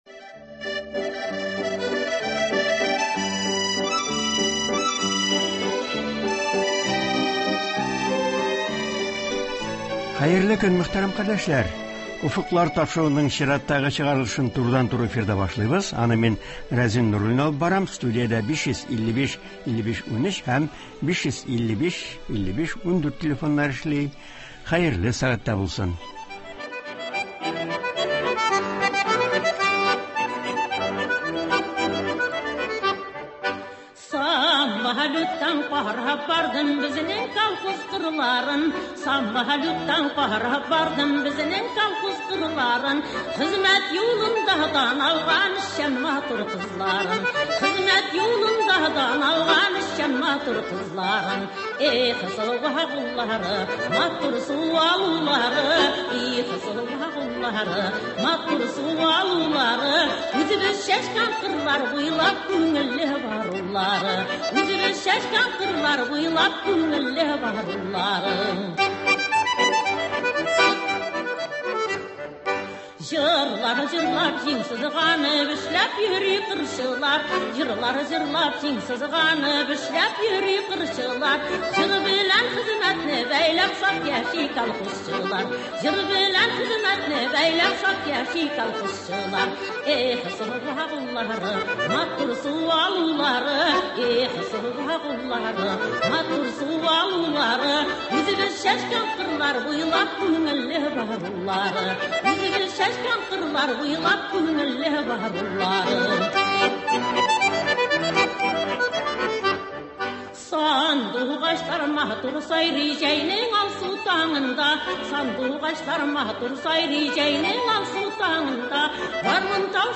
турыдан-туры эфирда